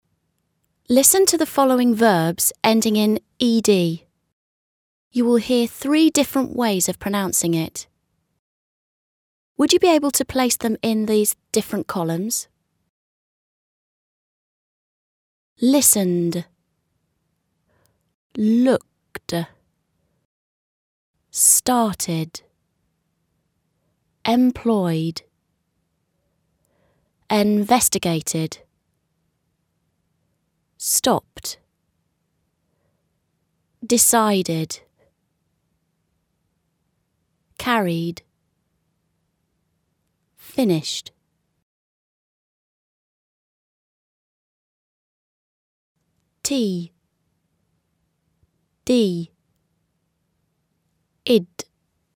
Listen to the following verbs ending in -ed. You will hear three different ways of pronouncing it.